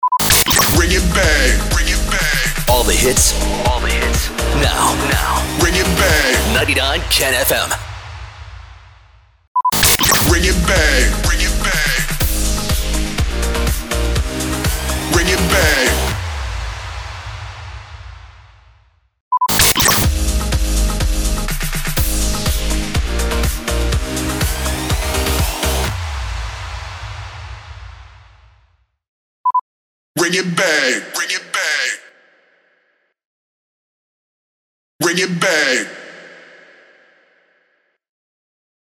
355 – SWEEPER – REJOIN